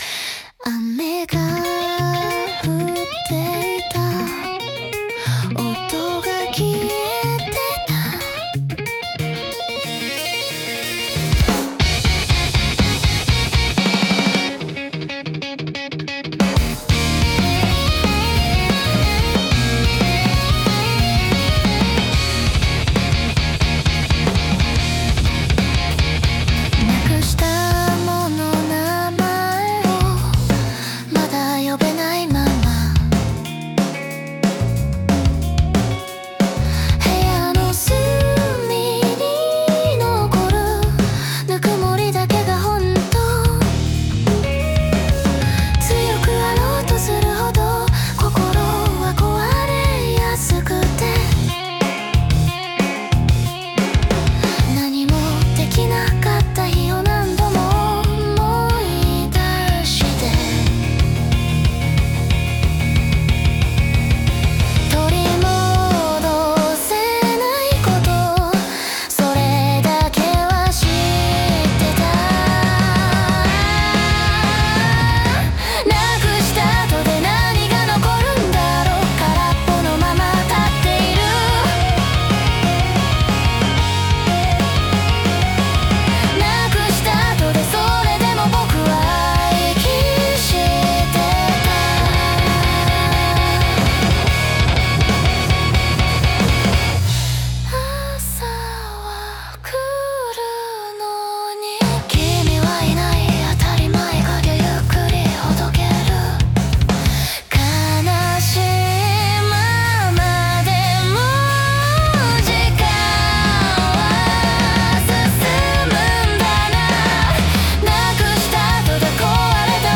女性ボーカル
イメージ：J-ROCK,女性ボーカル,かっこいい,優しい,切ない